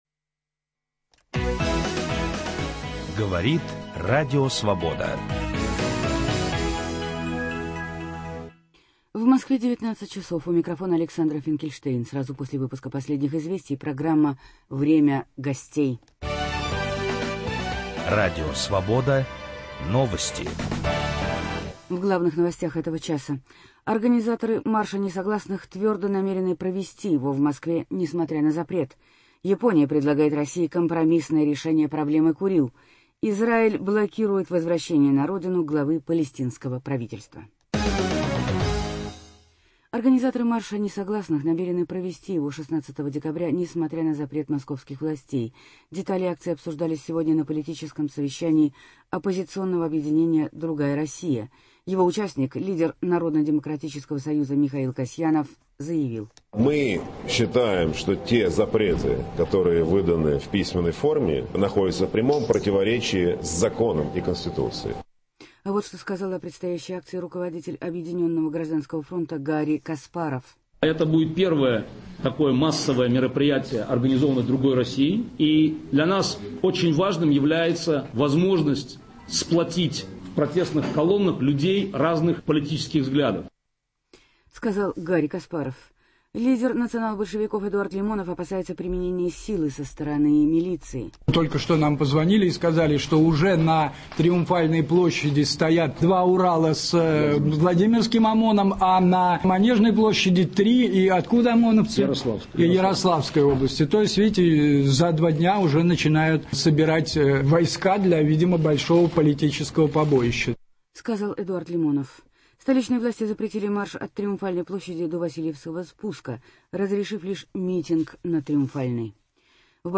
В киевской студии Радио Свобода - председатель Украинской главной координационной Рады, поэт Дмитро Павлычко.